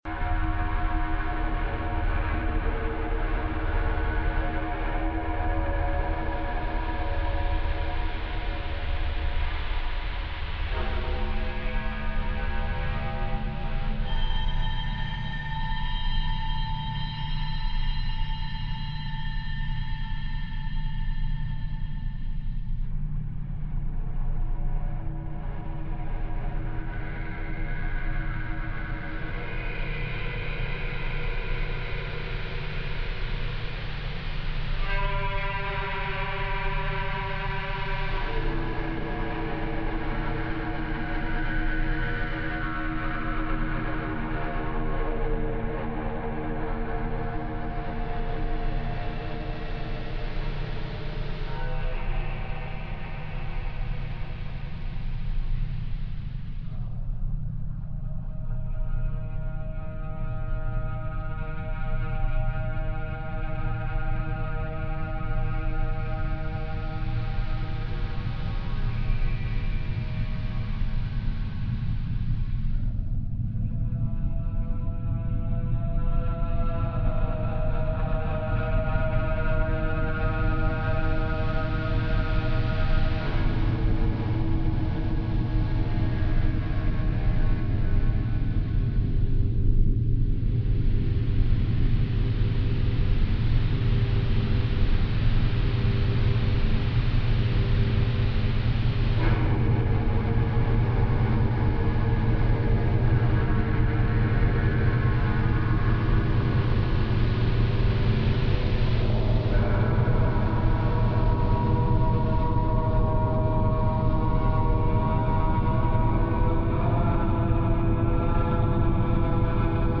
Genre: Dark Ambient, Drone.